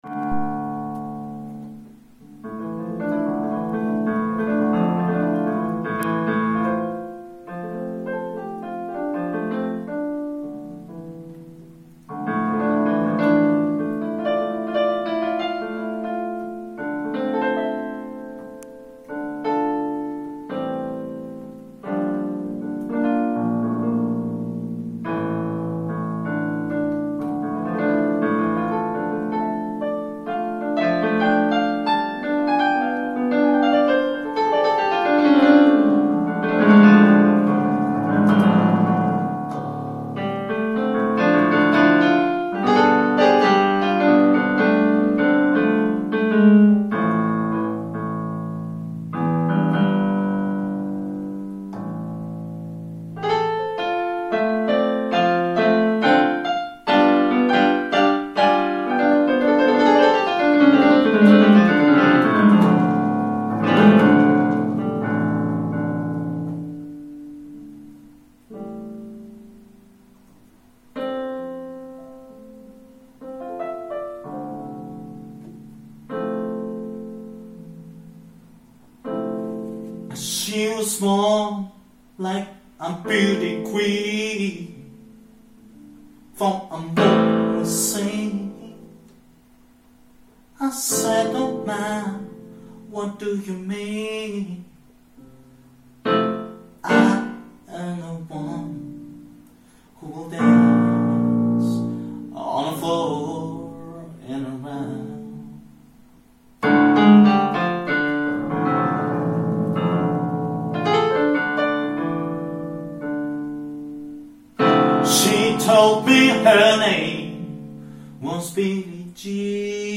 Lounge версия